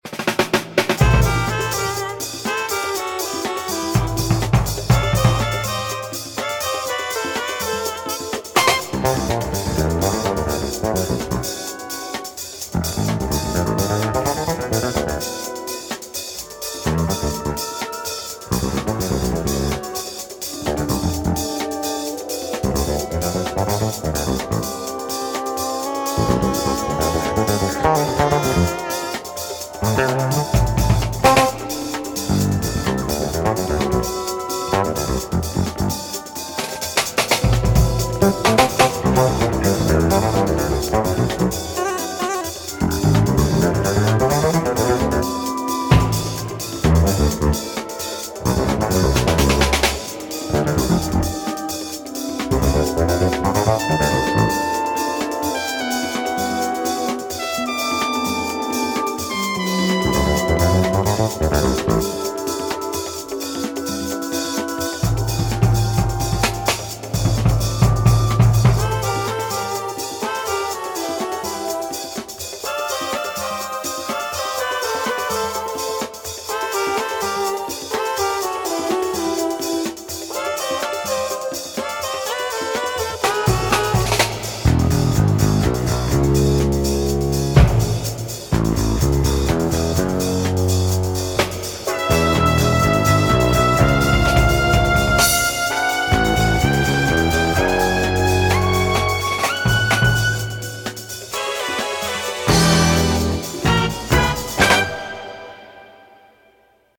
BPM122-132
MP3 QualityMusic Cut